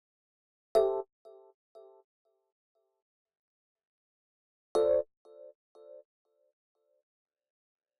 29 ElPiano PT3.wav